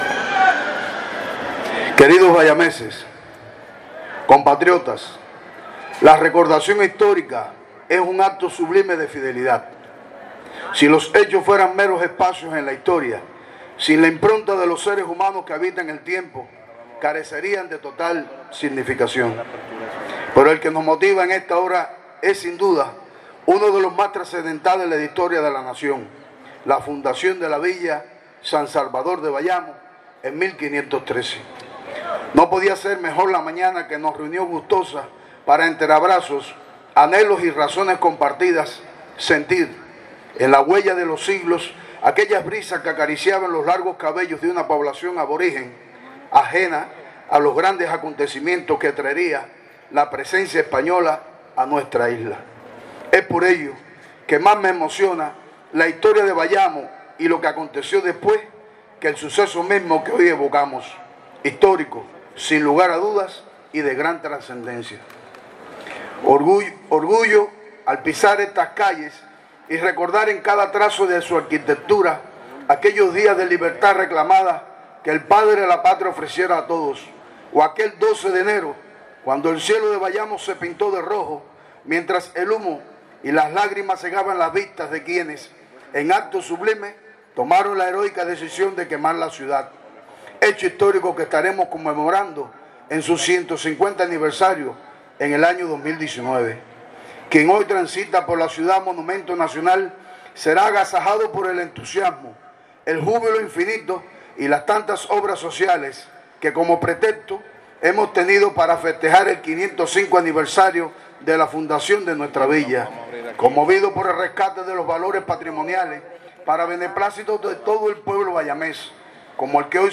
Con la tradicional ceremonia de las banderas y la colocación de sendas ofrendas florales en los monumentos erigidos en la Plaza de la Revolución de Bayamo a Carlos Manuel de Céspedes y Perucho Figueredo, inició hoy la jornada de celebración del aniversario 505 de la fundación de la Villa San Salvador.
En sus palabras de apertura Samuel Calzada Deyundé, presidente de la Asamblea municipal del Poder Popular en la segunda villa establecida en la Isla por el Adelantado Diego Velázquez, expresó el profundo orgullo de los habitantes de la urbe sede de relevantes acontecimientos de la historia de Cuba y cuna de los iniciadores de la gesta independentista.
Palabras-de-Samuel-Calzada-Deyundé-presidente-de-la-Asamblea-municipal-del-Poder-Popular-en-Bayamo.mp3